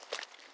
Water Walking 1_05.wav